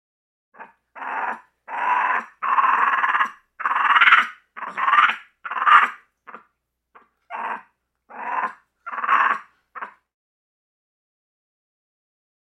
Monkey, Capuchin Growls, Chatter. Low Pitched, Scratchy Growl / Moans. Close Perspective.